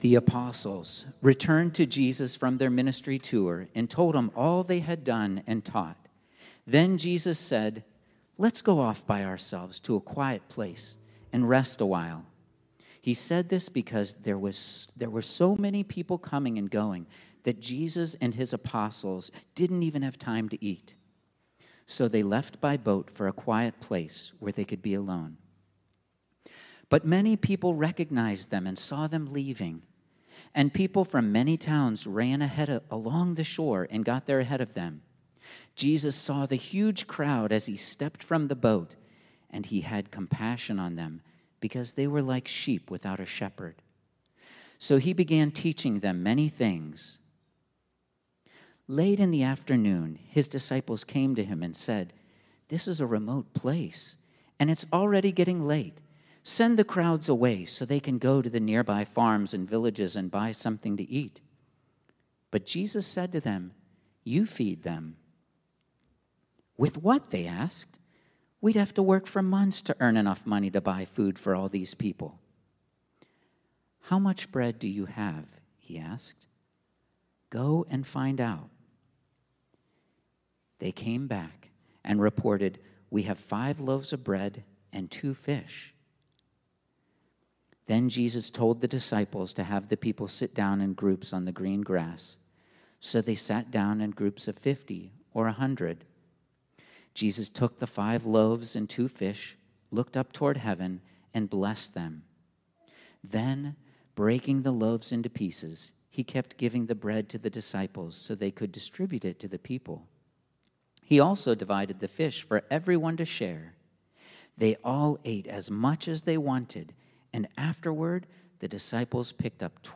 Jesus Unfiltered -- Real Food for Real LIfe (Mark 6:30-44) | Upper St. Clair Alliance Church